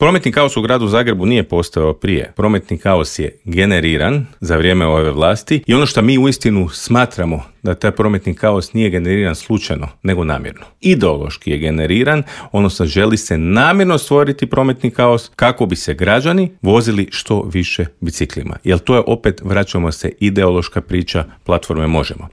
U metropoli po drugi mandat ide aktualni gradonačelnik Tomislav Tomašević, a gradonačelničkog kandidata HDZ-a Mislava Hermana ugostili smo u Intervjuu Media servisa.